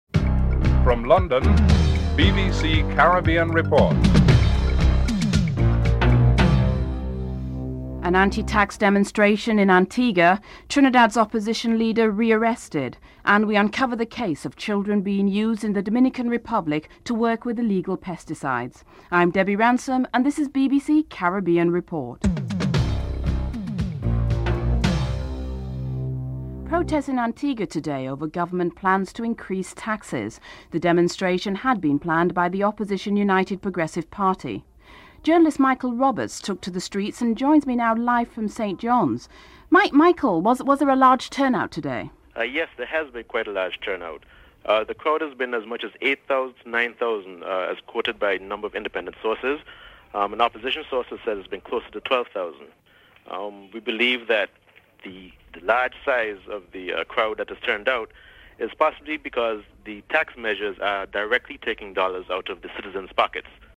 10. Interviews with two children who actually work as fumigators in the agricultural industry (08:03-09:36)
12. Report on the government reshuffle in Cuba (10:36-11:08)